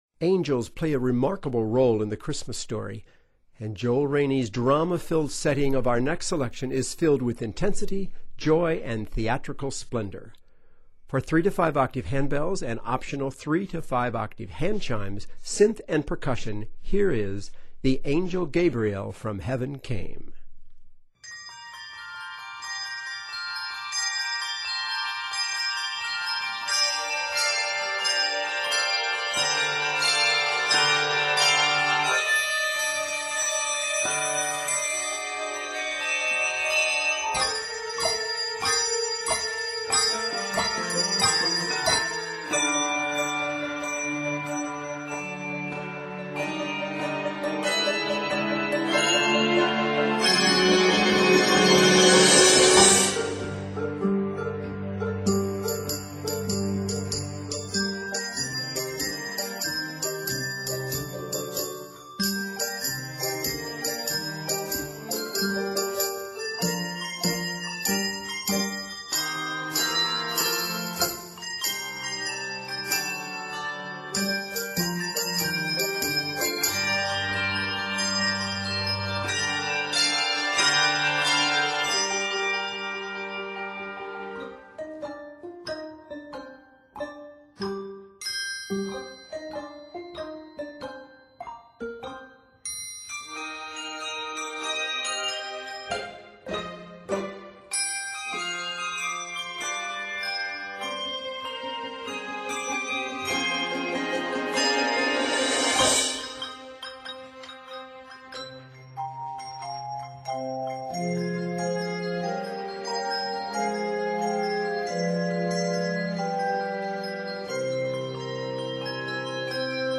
filled with intensity, joy, theatrical splendor and wonder